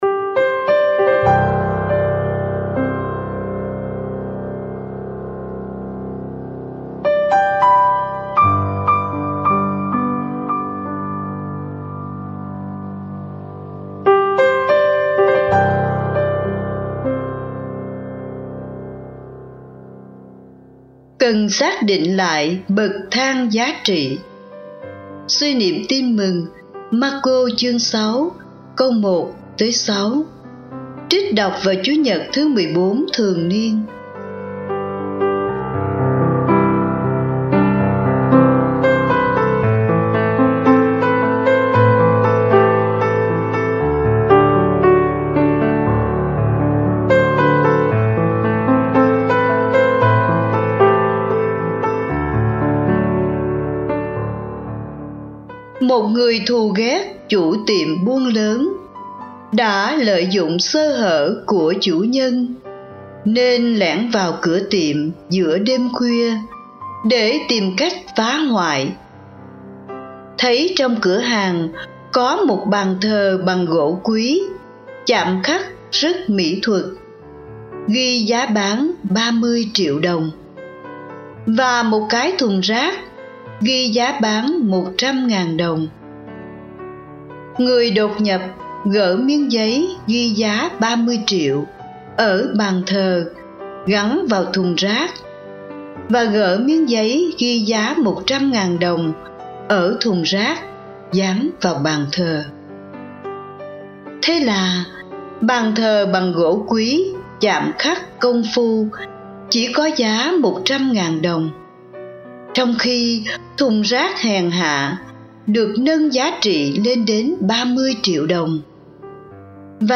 Cần xác định lại bậc thang giá trị (Suy niệm Tin mừng Mác-cô (6, 1-6) trích đọc vào Chúa nhật 14 thường niên